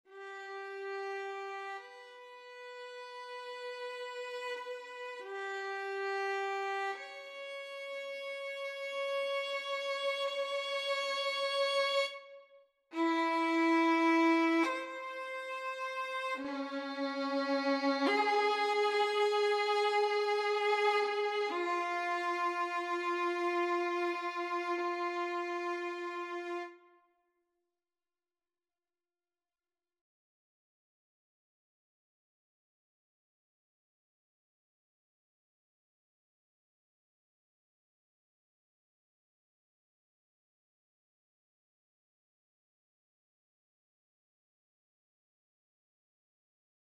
Er is hier sprake van een vrij eenvoudige gelaagdheid, voorgrond en achtergrond.
Hij is uitvergroot, de intervallen zijn vaak groot:
Er is geen vaste maat.
Iedere keer als de melodie een langere toon heeft, een ophangpunt, hoor je een tel erna een akkoord.
Het eerste motief begint op een licht moment, stijgt als een vraag en eindigt op een zwaar moment. Het tweede motief heeft een veel langere opmaat, maar daalt als een antwoord en eindigt licht.
Op het einde horen we in het woord ‘zoeken’, een dalende grote secunde.